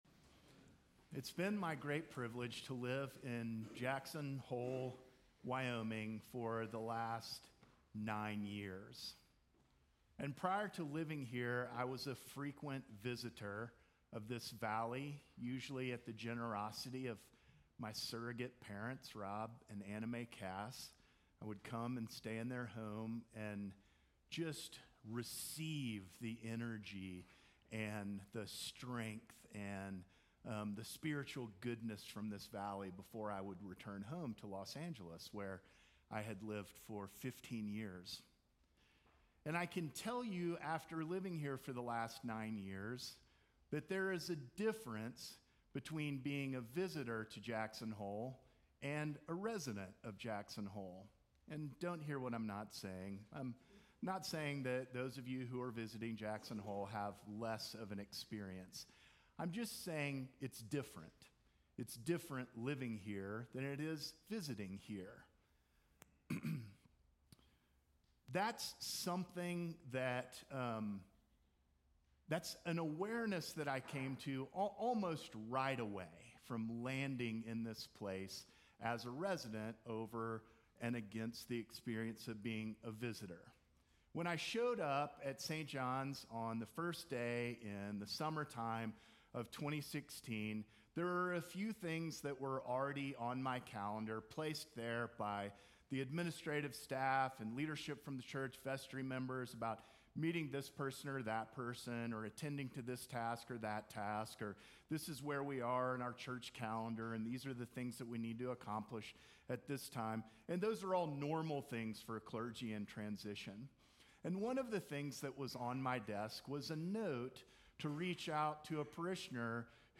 Sermons from St. John's Episcopal Church The Epiphany